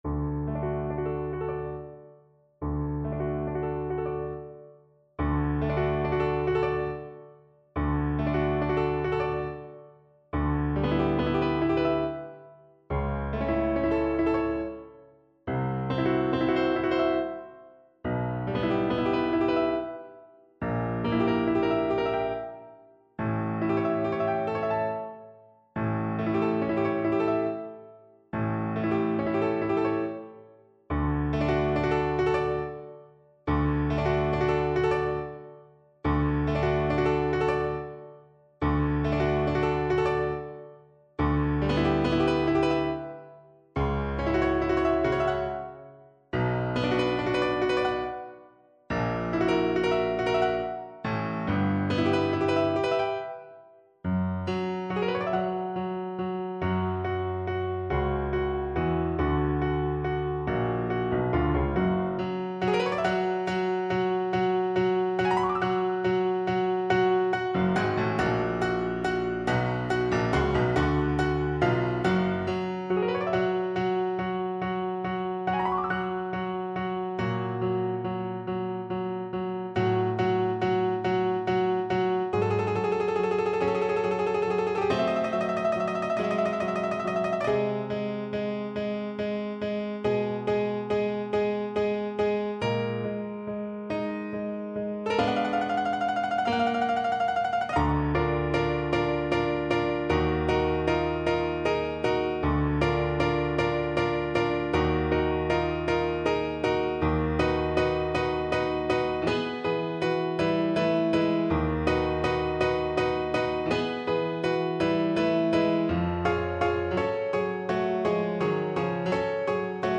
Adagio maestoso
Classical (View more Classical Viola Music)